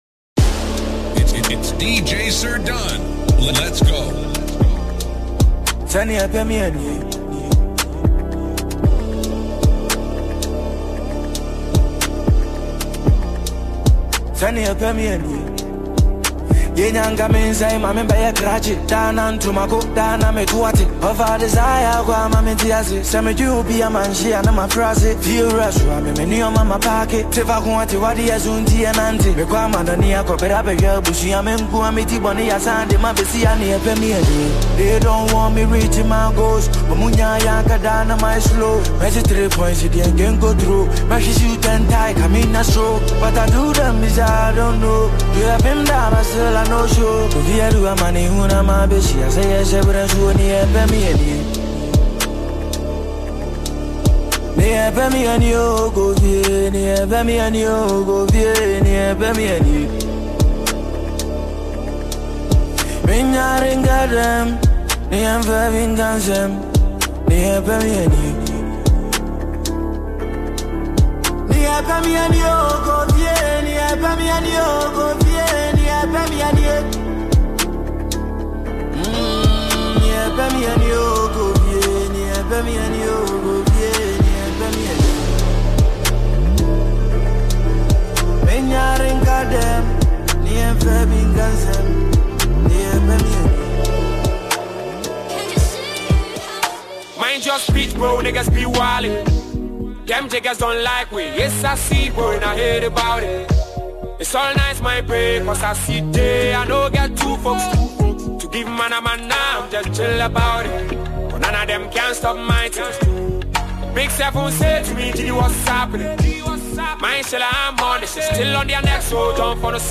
mixtape
Genre: Mixtape